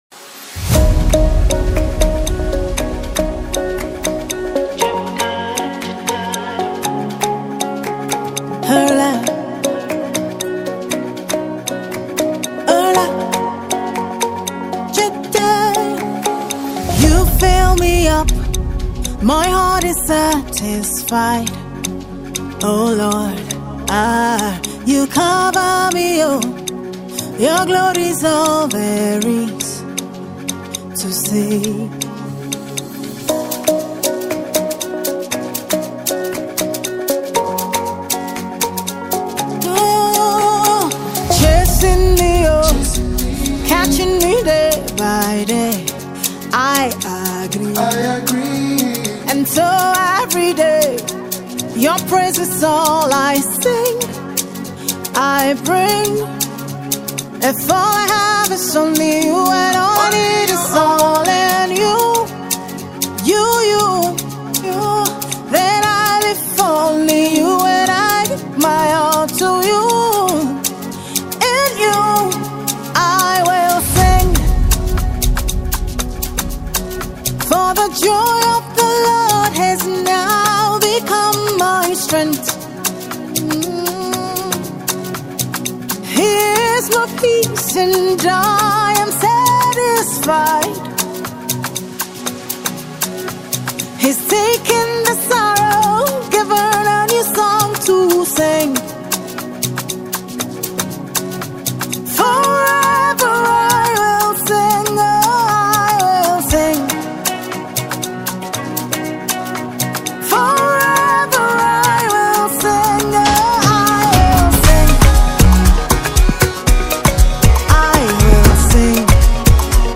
a powerful and marvelous refreshing melody